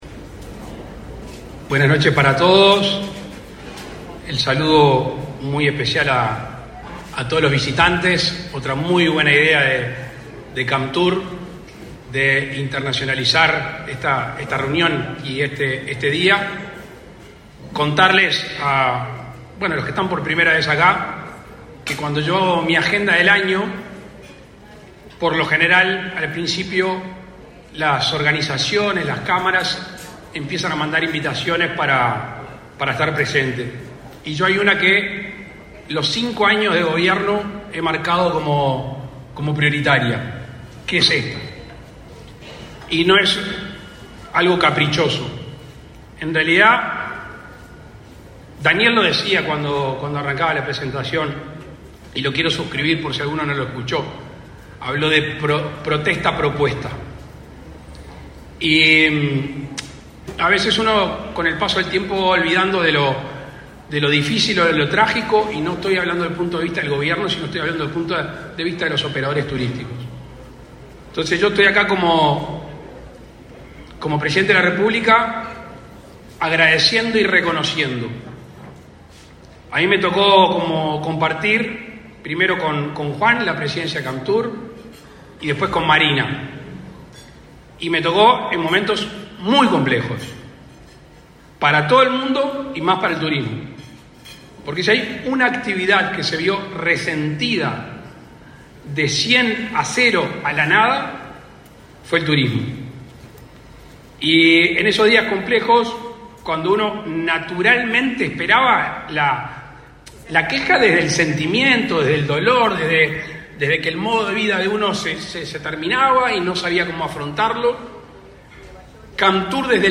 Palabras del presidente de la República, Luis Lacalle Pou
Con la presencia del presidente de la República, Luis Lacalle Pou, se conmemoró, este 27 de setiembre, el Día Mundial del Turismo.